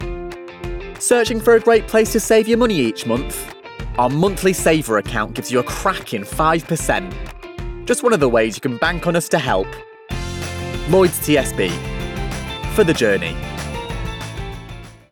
Voice Reel
Lloyds - Clear, Informative